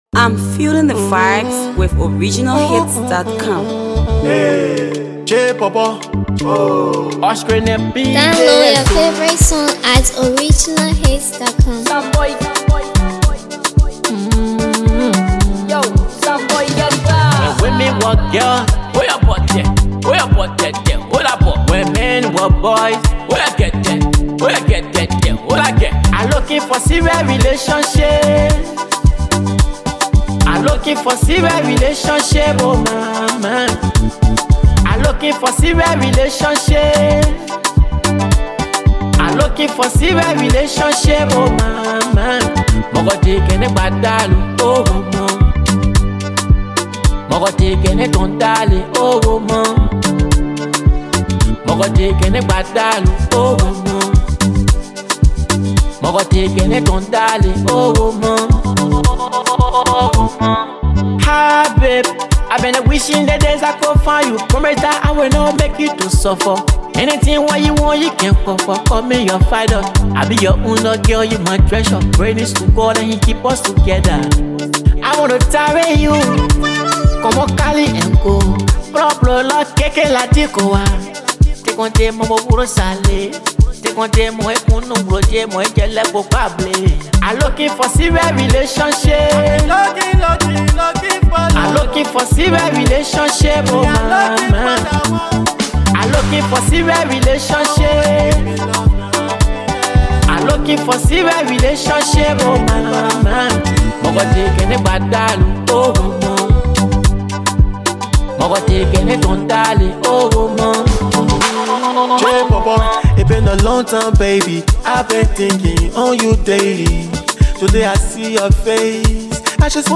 ” featuring award-winning Gbema artist